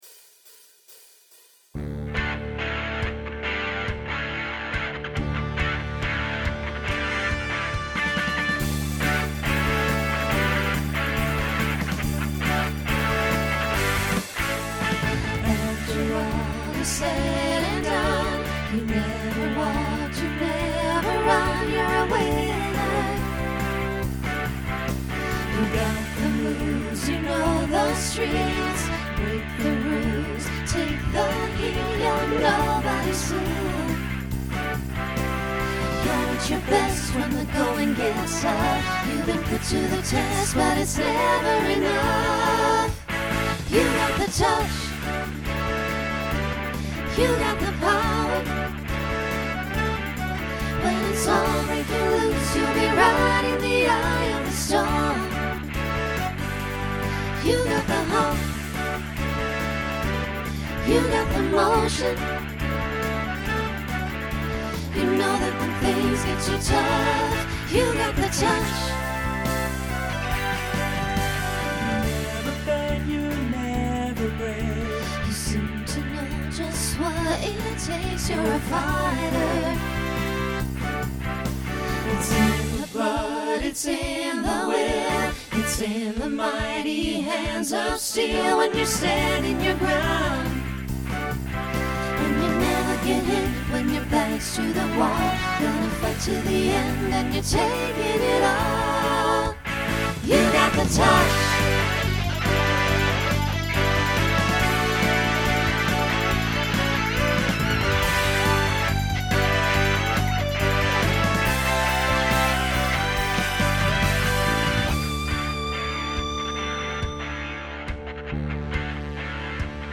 Genre Rock Instrumental combo
Voicing SAB